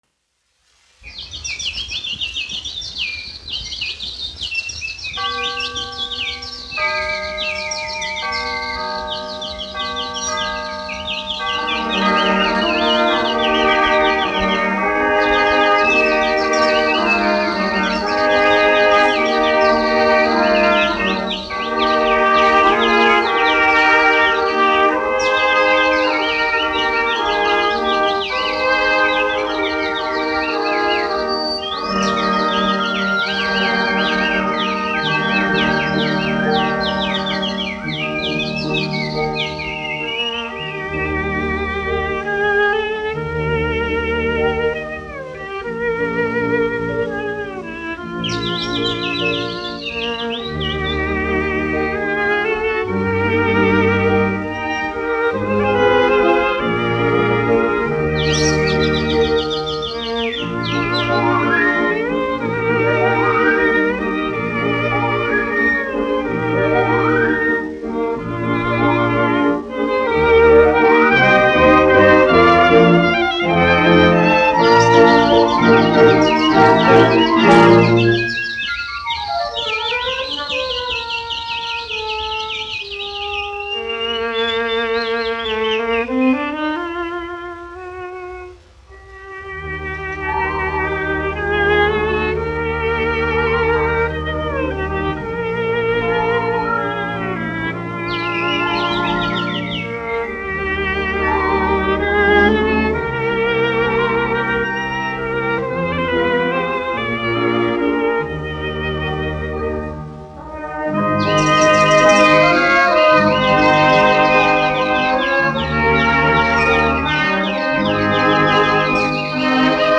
1 skpl. : analogs, 78 apgr/min, mono ; 25 cm
Orķestra mūzika